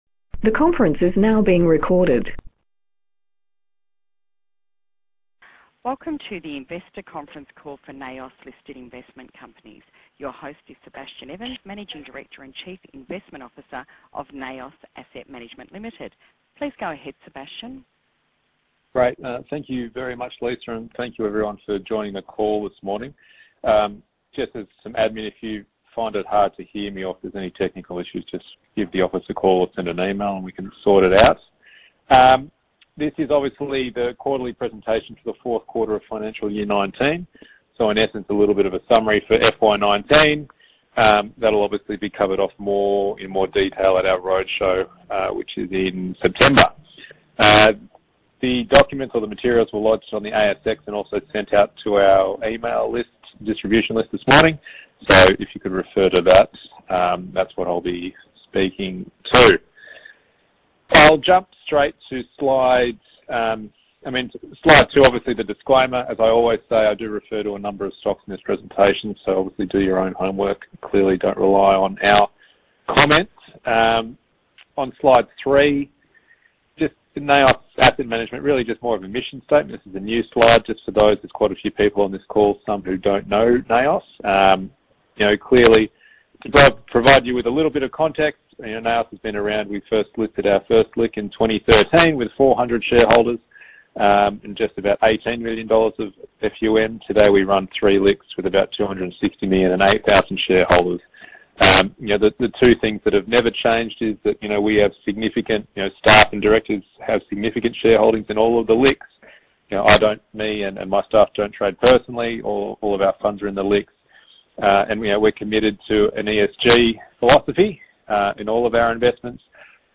NAOS Q4 Financial Year 2019 Conference Call | Presentation Materials & Call Recording
For those who missed our investor conference call you may listen to a recording of the call here.